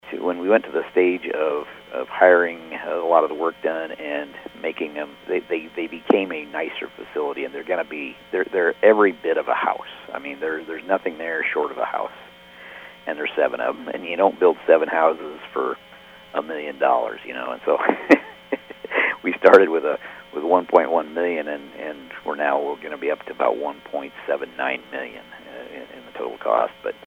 Sorensen says the discussion centered around where they started with the budget, where they are now, and how they got there. He says the supervisors decided to add another $290,000 to the budget to complete the project and get it all operational this spring.